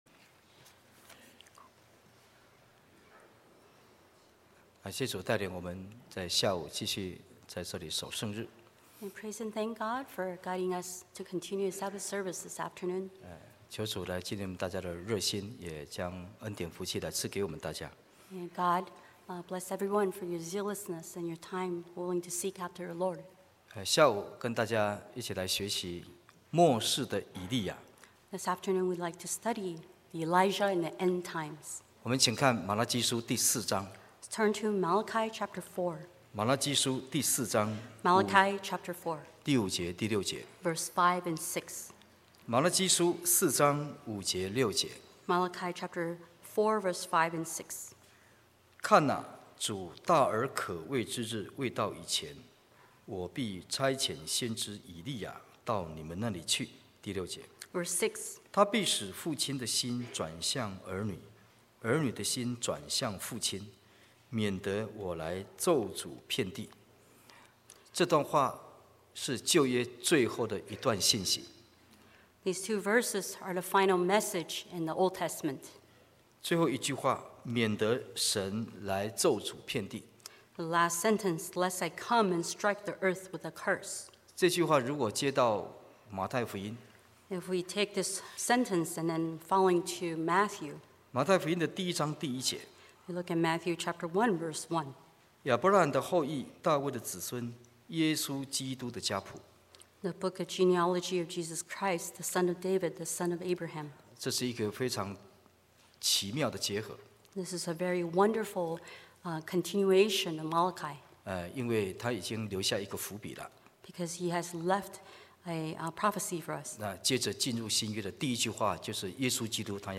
TJC True Jesus Church audio video sermons Truth Salvation Holy Spirit Baptism Foot Washing Holy Communion Sabbath One True God